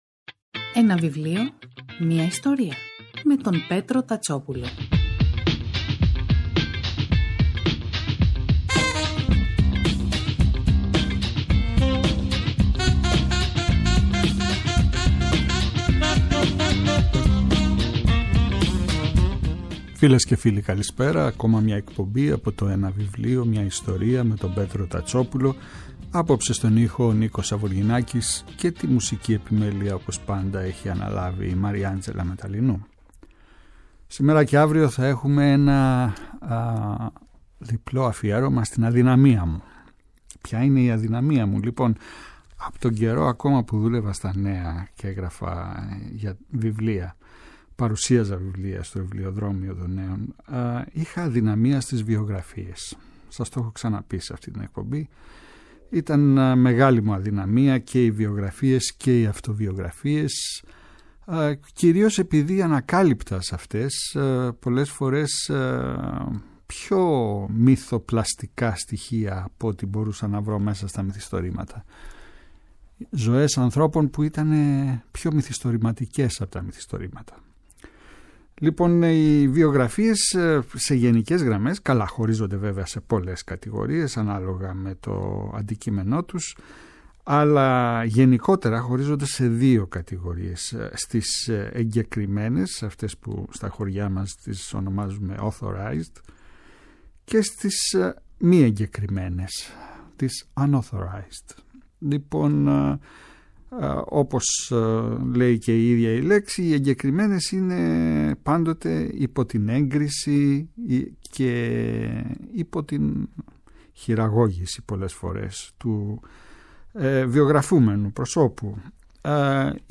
Κάθε Σάββατο και Κυριακή, στις 5 το απόγευμα στο Πρώτο Πρόγραμμα της Ελληνικής Ραδιοφωνίας ο Πέτρος Τατσόπουλος , παρουσιάζει ένα συγγραφικό έργο, με έμφαση στην τρέχουσα εκδοτική παραγωγή, αλλά και παλαιότερες εκδόσεις.